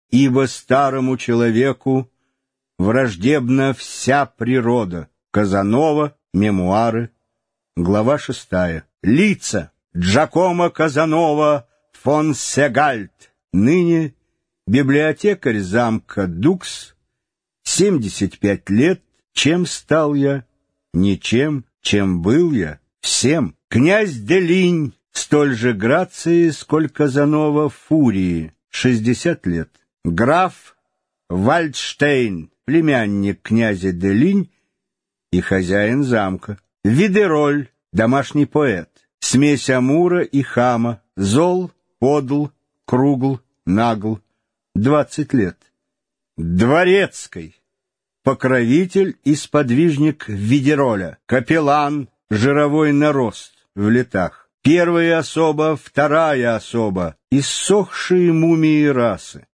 Аудиокнига Феникс | Библиотека аудиокниг